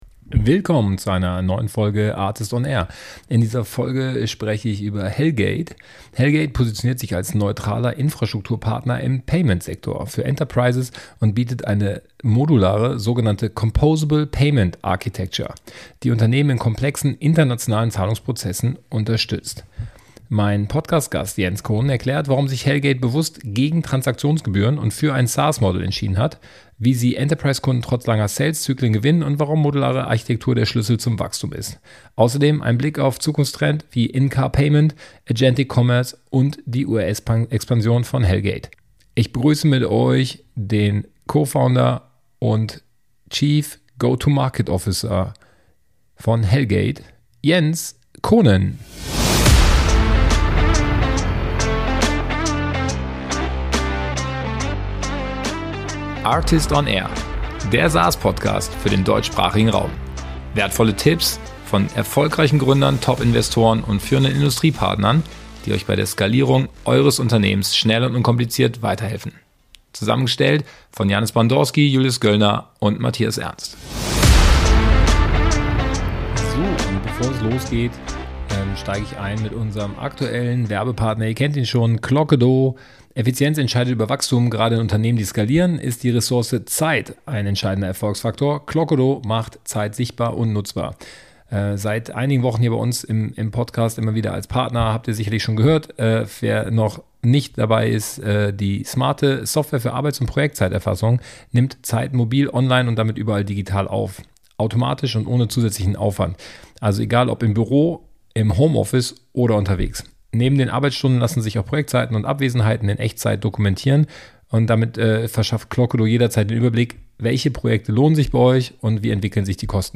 We talk about building software companies in Europe. In the ARRtist on AIR podcast, successful B2B SaaS founders, top investors, leading industry partners and experts provide their hard learnings, valuable tips and insights beyond the obvious.